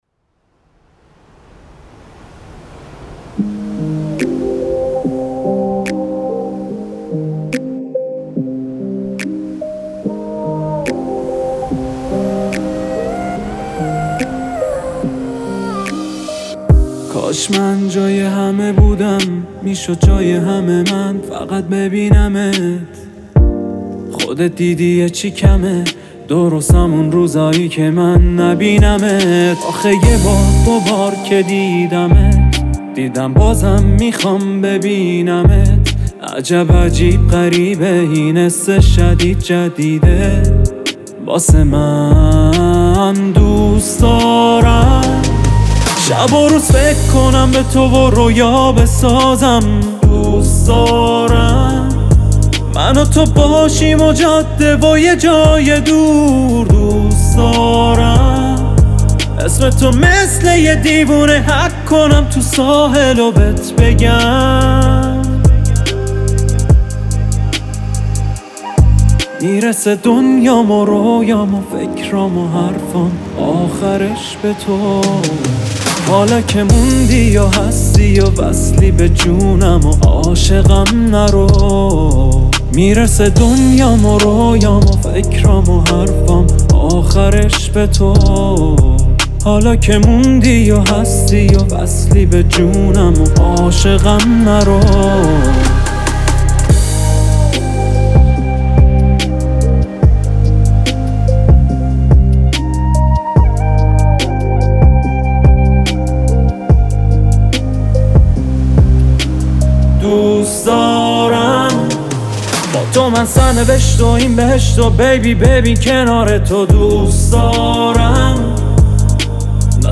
خواننده ، آهنگساز و نوازنده ویولون
موسیقی پاپ ایران